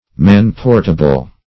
Meaning of man-portable. man-portable synonyms, pronunciation, spelling and more from Free Dictionary.